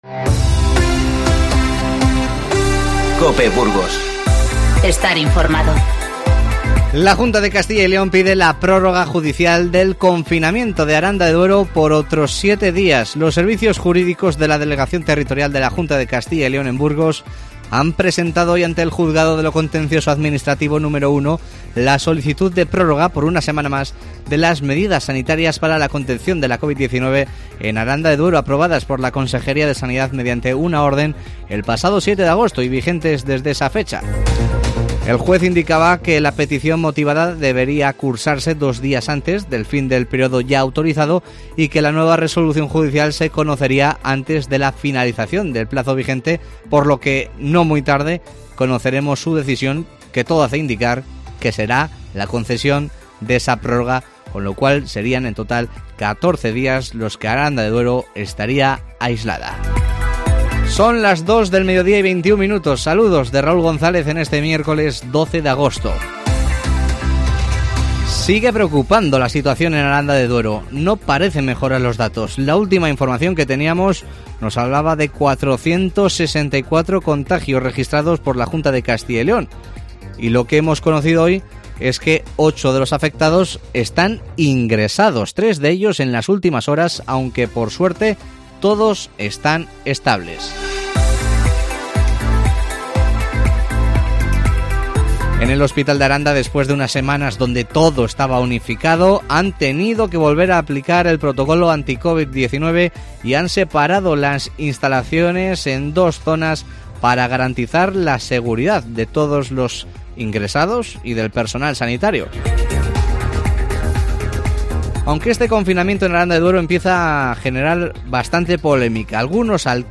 Informativo 12-08-20